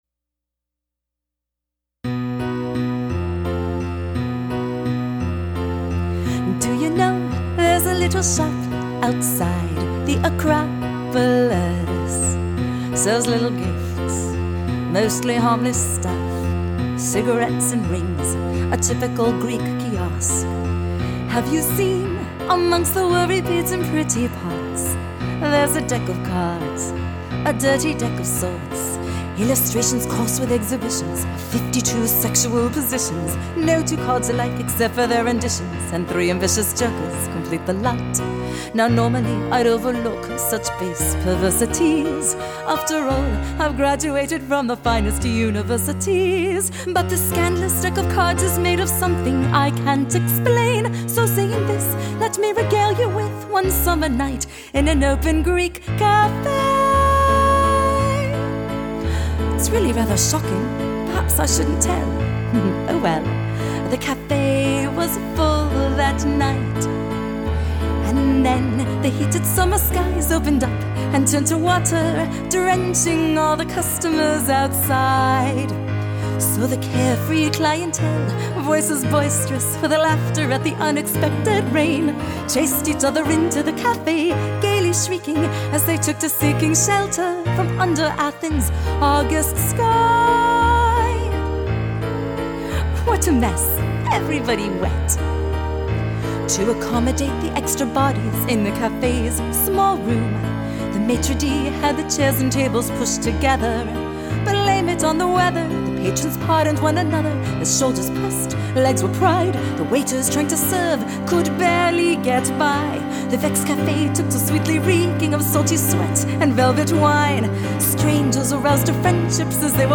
a bawdy comic operetta
for mezzo-soprano and drunk chorus